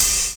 DISCO 1 OH.wav